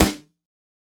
Snare 006.wav